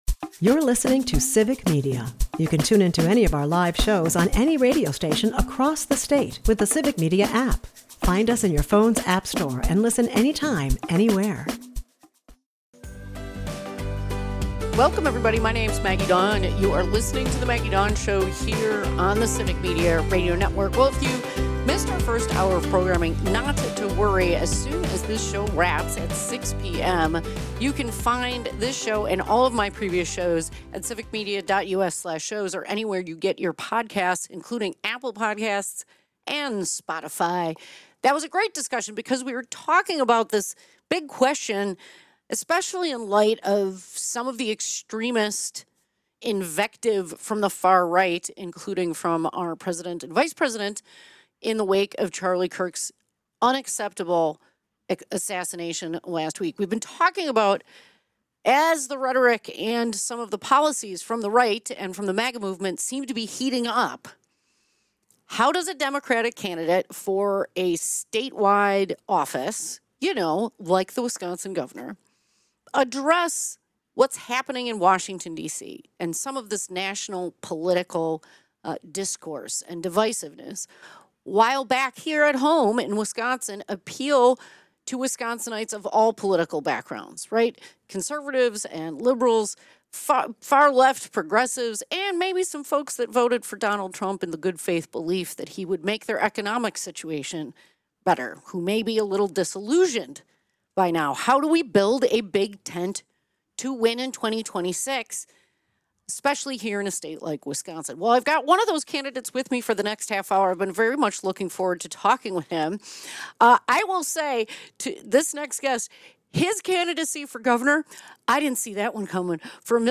a part of the Civic Media radio network and airs Monday through Friday from 2-4 pm across the network